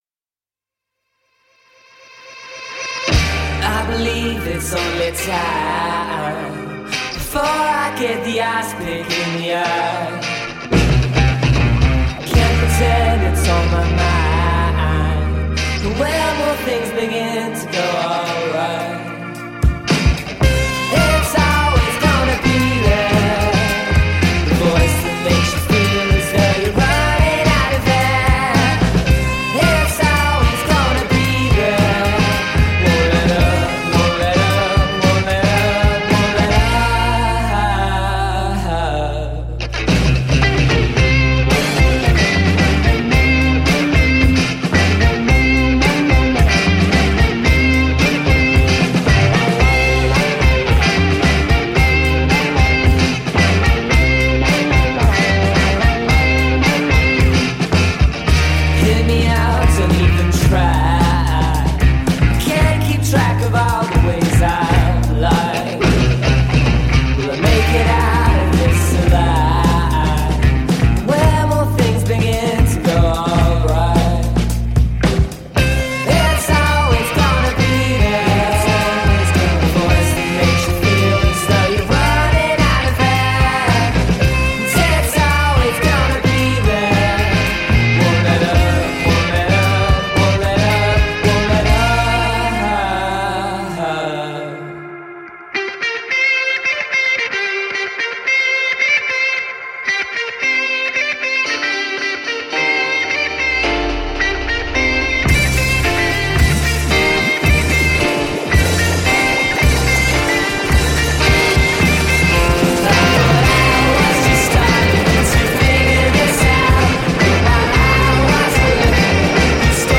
Psych outfit